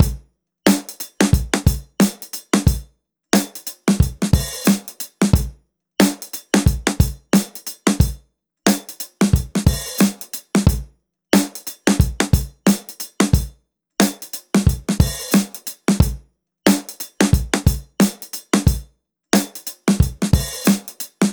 À partir d’une petite boucle de batterie, voici comment travailler sur une pulsation avec GSAP.
Pour le son, je copie/colle pour créer une répétition.
Quest_90_Swing_Dry02-4x